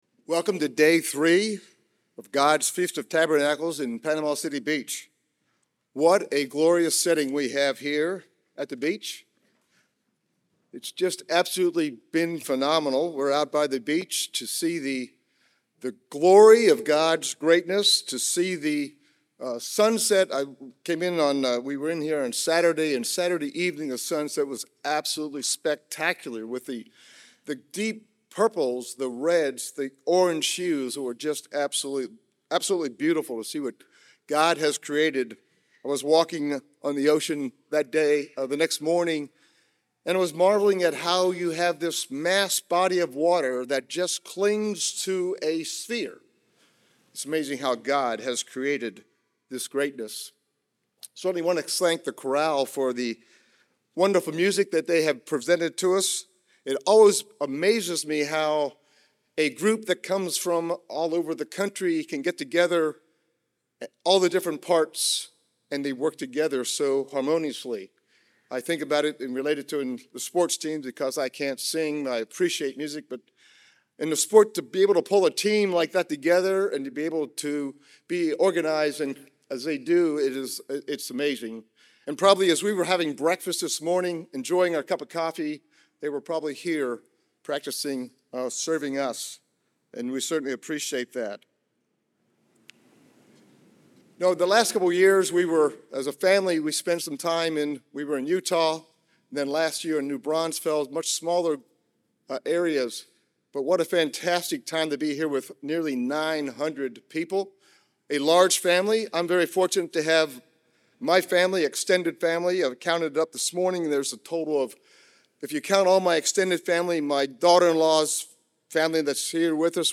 This sermon was given at the Panama City Beach, Florida 2022 Feast site.